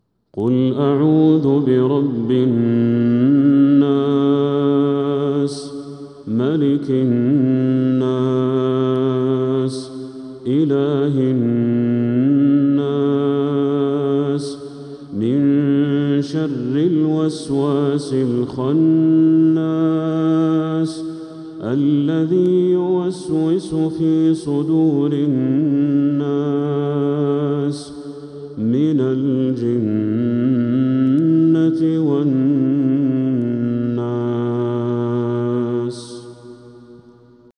سورة الناس كاملة | ذو الحجة 1446هـ > السور المكتملة للشيخ بدر التركي من الحرم المكي 🕋 > السور المكتملة 🕋 > المزيد - تلاوات الحرمين